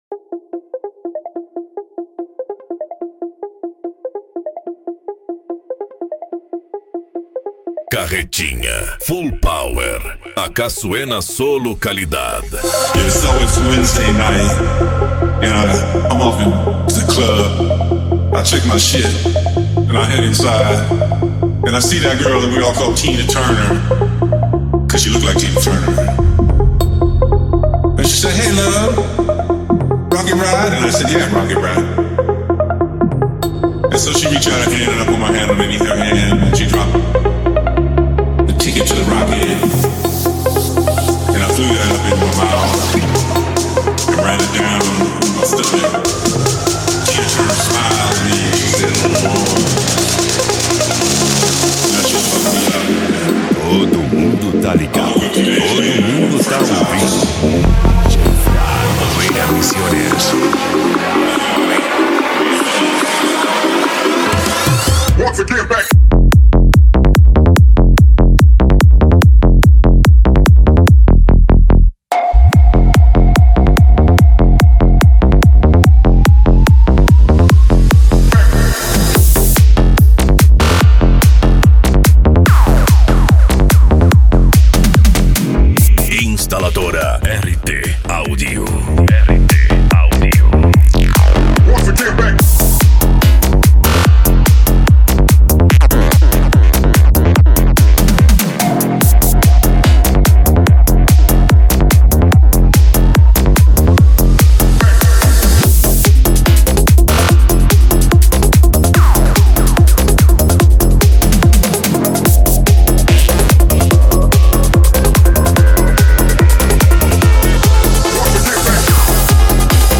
PANCADÃO
Remix